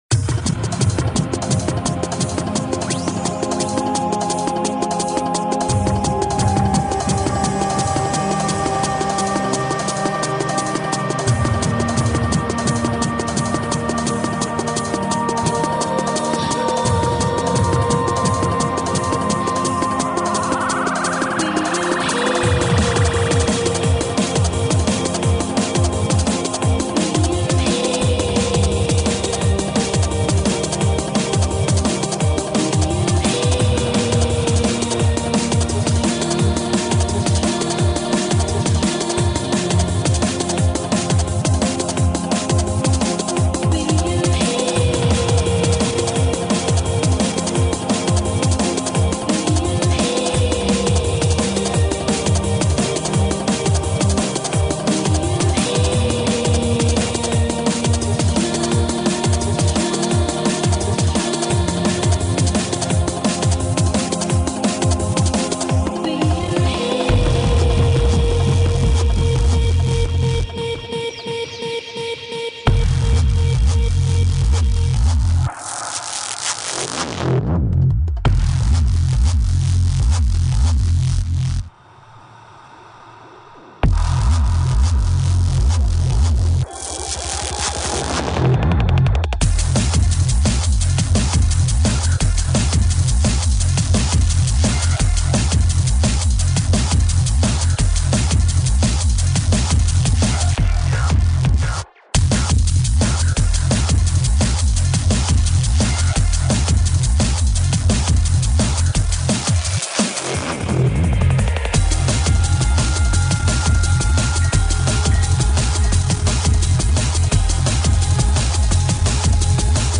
Главная » Drum & Bass